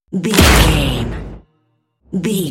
Dramatic hit drum metal
Sound Effects
Atonal
heavy
intense
dark
aggressive
hits